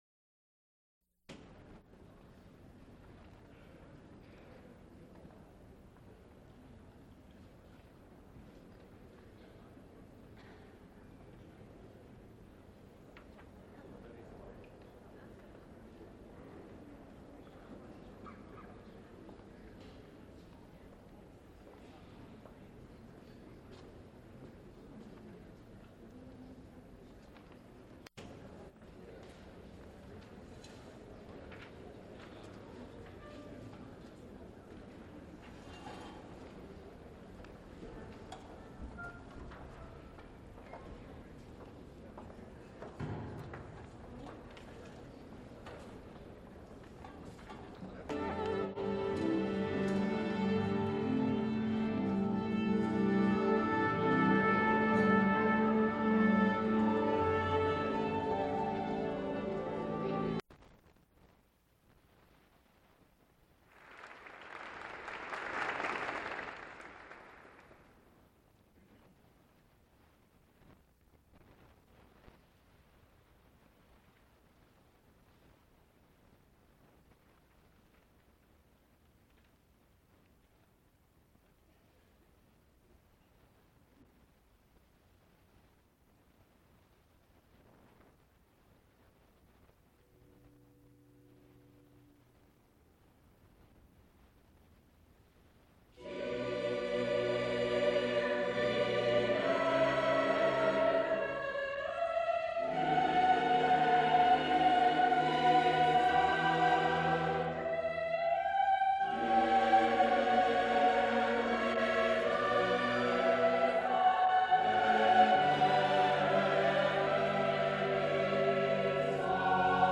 Part II., Bach., No program or listing in the University Times.., Recorded live 1975, University of Pittsburgh., Bach, Johann Sebastian, 1685-1750.
musical performances